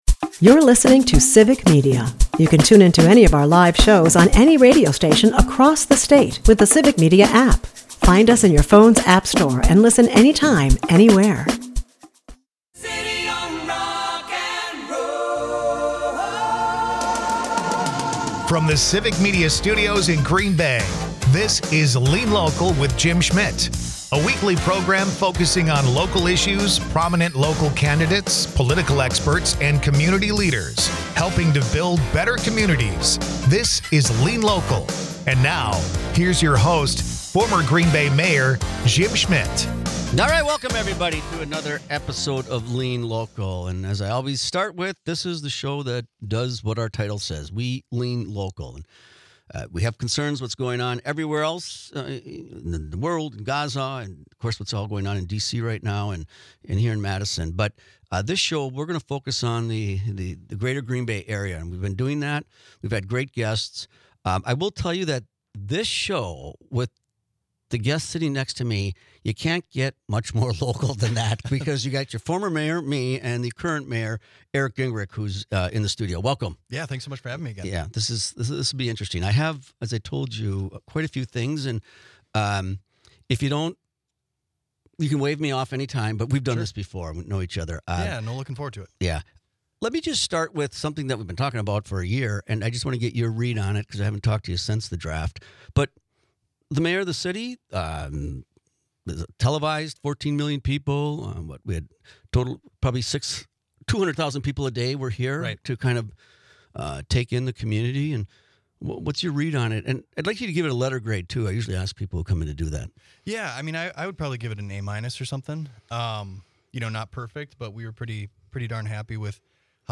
Former Green Bay Mayor, Jim Schmitt is joined by the current Mayor of Green Bay, Eric Genrich. Jim asks Eric how he thought the 2025 NFL Draft went and the events around it. Jim and Eric talk about Coal Piles, the new Public Market, and parking.